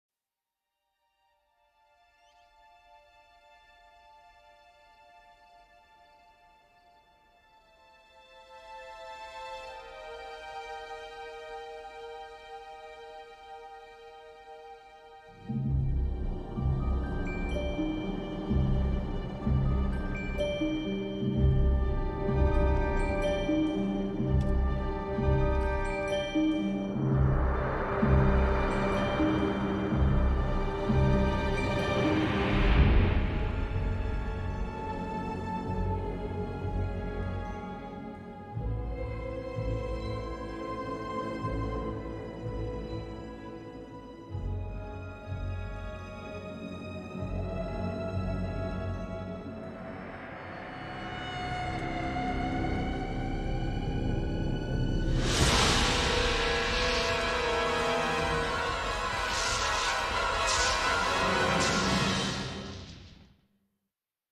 Original Score